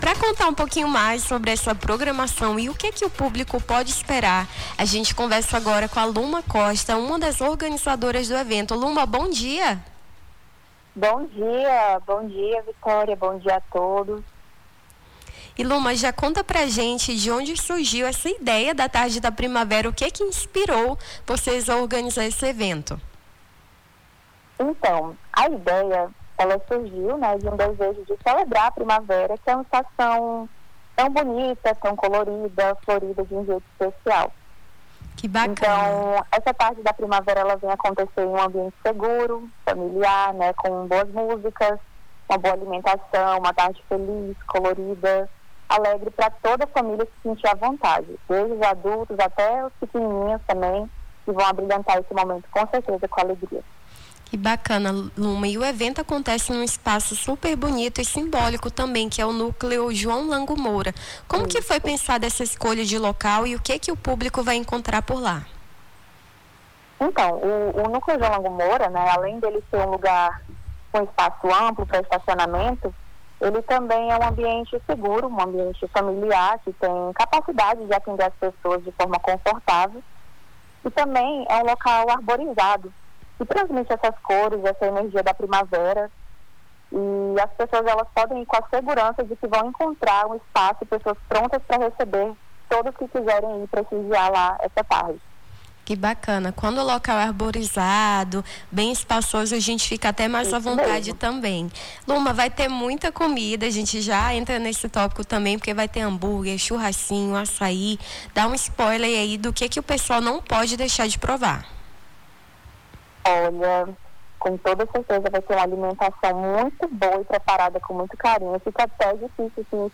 Nome do Artista - CENSURA - ENTREVISTA (TARDE DA PRIMAVERA) 10-10-25.mp3